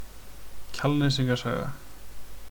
Kjalnesinga saga (Icelandic pronunciation: [ˈcʰalˌnɛːsiŋka ˈsaːɣa]